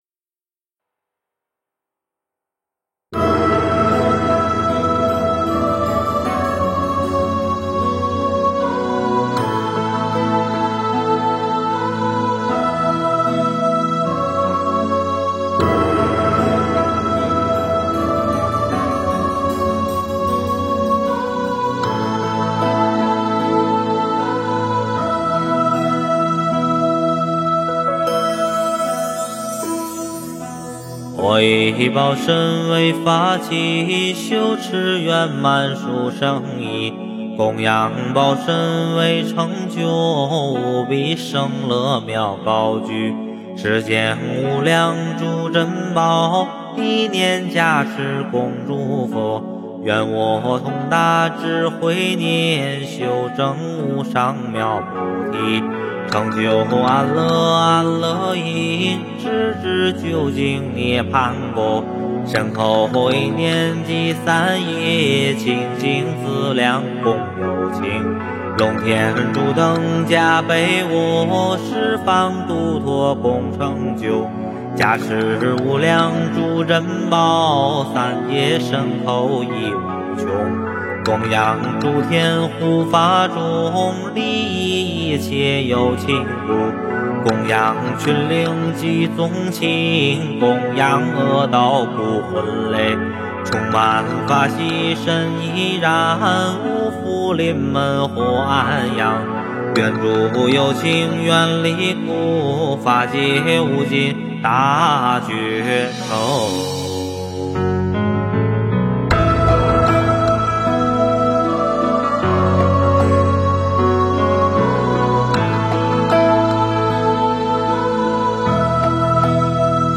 诵经
佛音 诵经 佛教音乐 返回列表 上一篇： 安逸忏心 下一篇： 观音灵感真言(梦授咒